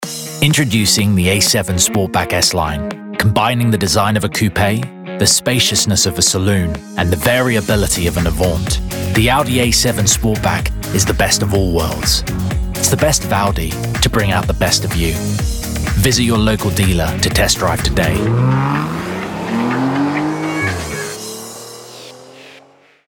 Voice Reel
Edgy, Clear, Confident